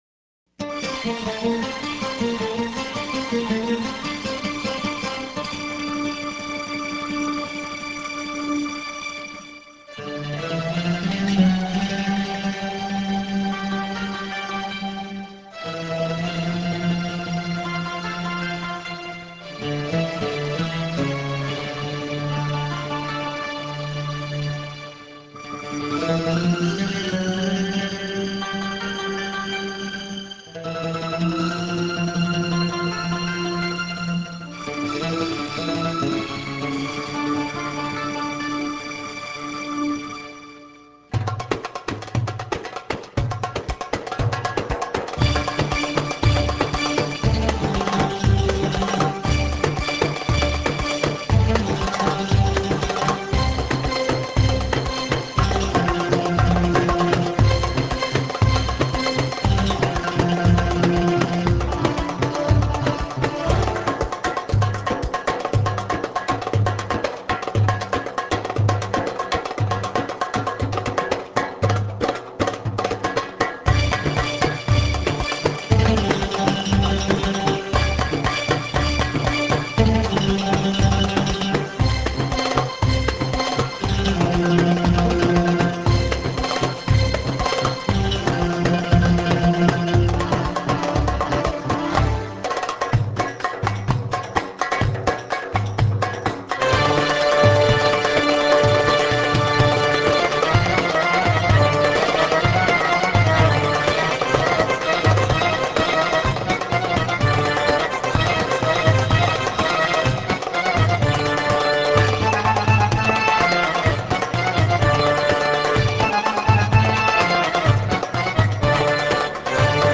Vocals, Oud, Accordion & Keyboard
Percussion
Tabla
Nay